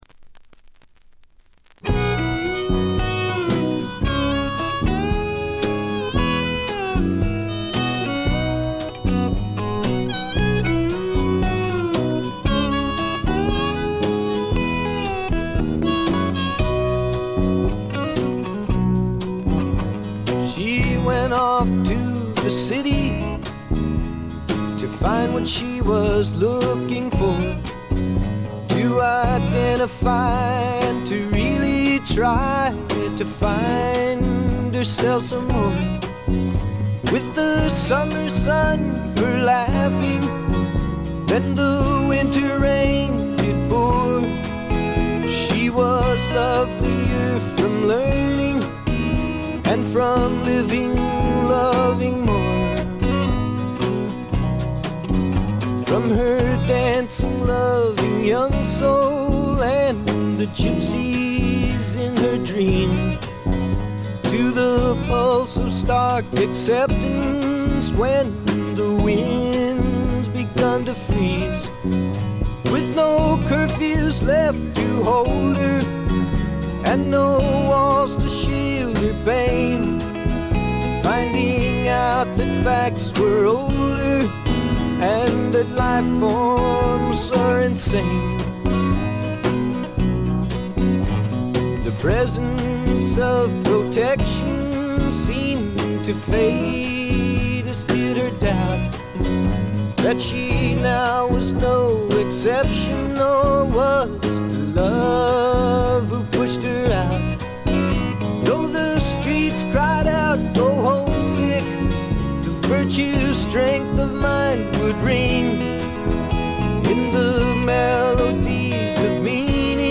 ほとんどノイズ感無し。
試聴曲は現品からの取り込み音源です。
Recorded at The Village Recorder